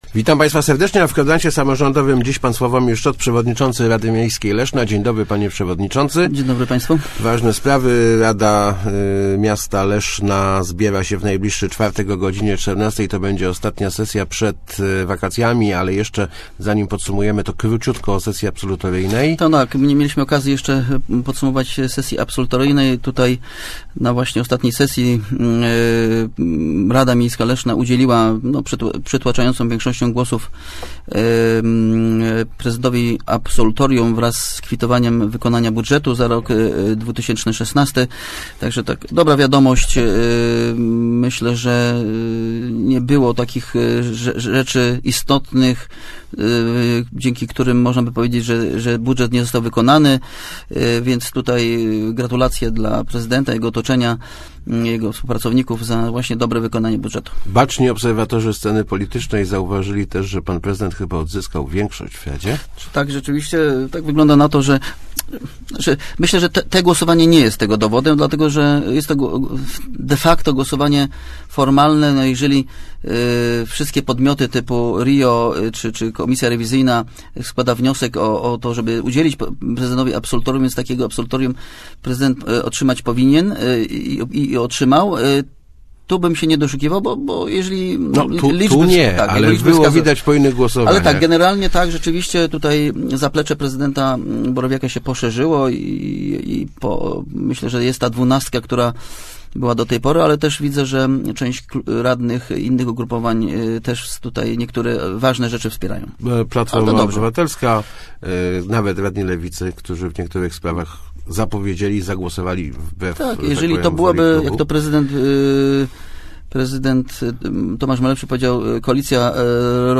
-Wnioskodawcy proponuj�, �eby podnie�� wynagrodzenia o od 5 do 8 procent - m�wi� w Kwadransie Samorz�dowym S�awomir Szczot, przewodnicz�cy Rady Miejskiej Leszna.